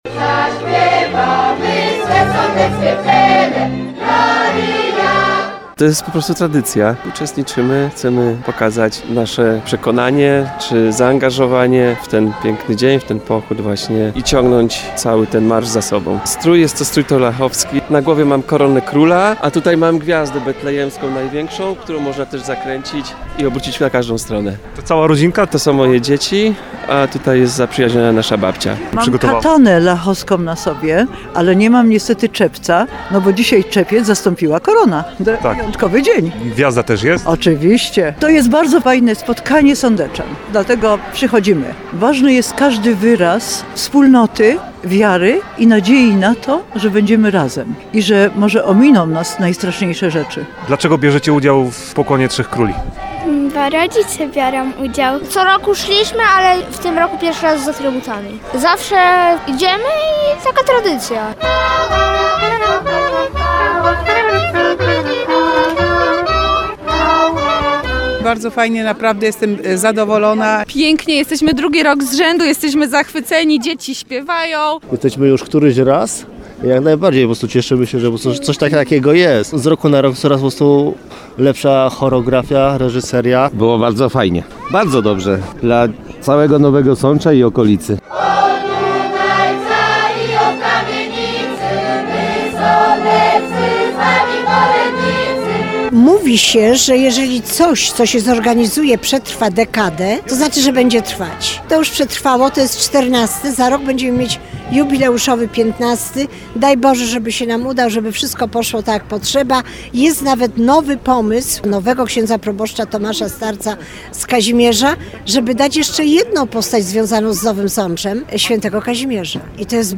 Po ulicach miasta niósł się śpiew kolęd, a wydarzeniu towarzyszyła wyjątkowa atmosfera.
Podczas wędrówki śpiew kolęd był przerywany występami aktorów, którzy wcielili się między innymi w Heroda, ale też św. Małgorzatę.